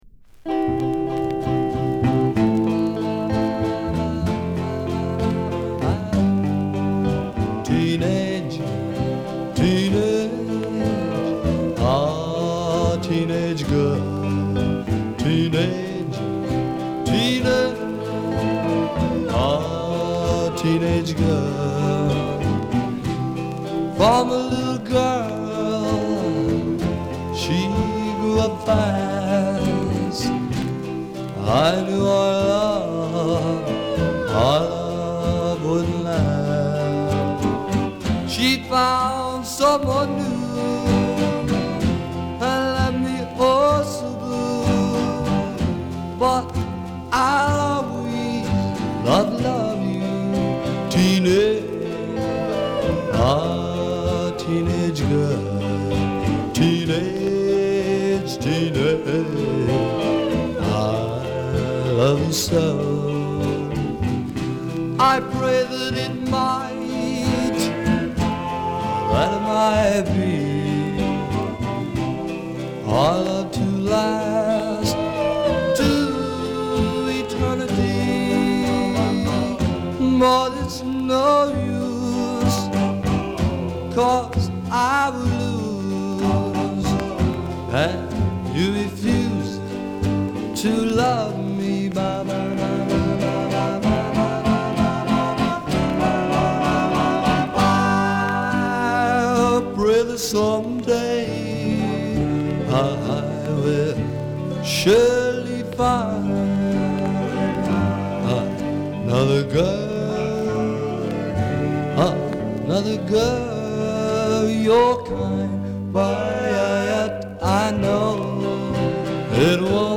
ジャングル・テーマのプリミティヴ・パウンダー。何気に性急で緊迫感のある演奏が素晴らしい。
[Comped] [Exotica] [NEW]